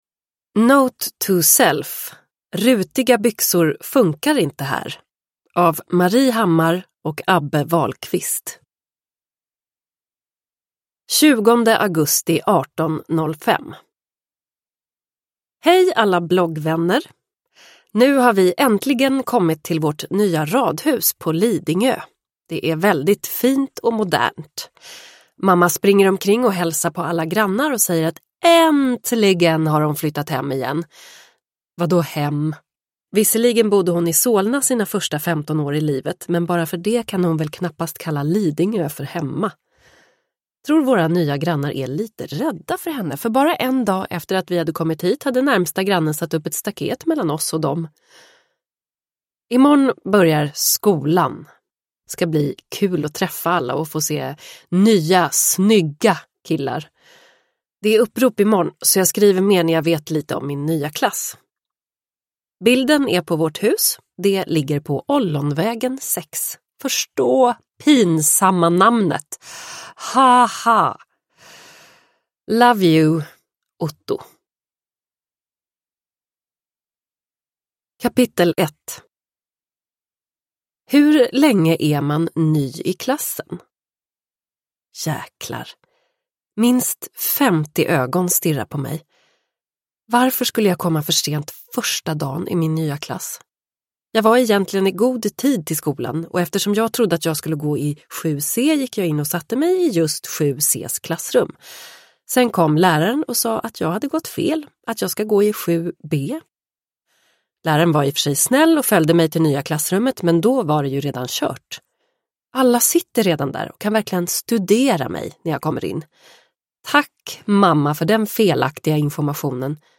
Note to self : rutiga byxor funkar inte här – Ljudbok – Laddas ner